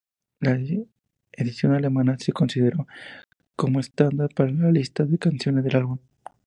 Read more Adj Noun Frequency C1 Hyphenated as es‧tán‧dar Pronounced as (IPA) /esˈtandaɾ/ Etymology Borrowed from English standard In summary Borrowed from English standard.